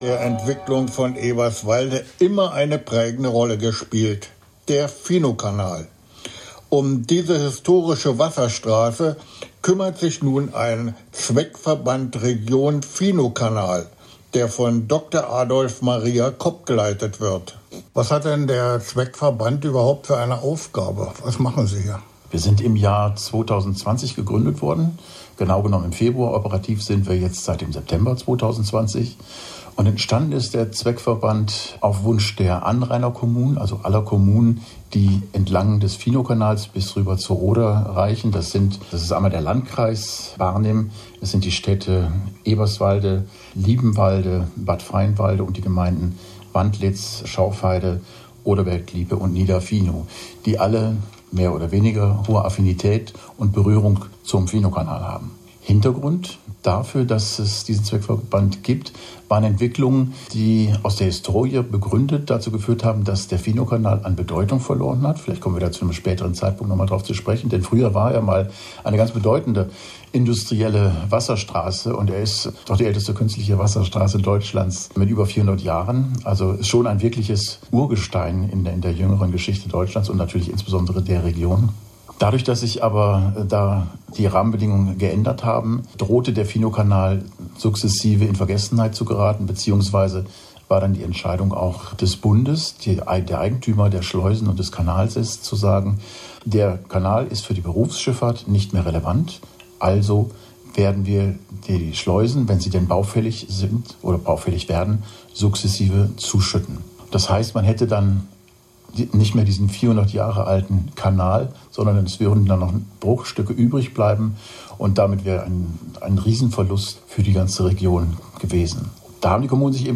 Aktuelle Informationen zu den Baumaßnahmen Radio Ginseng - Interview zur Schleusensanierung Im Dezember 2024 konnte man auf Radio Ginseng unter der Rubrik Brandenburger Orte verschiedene Interviews zu Attraktionen in Eberswalde hören.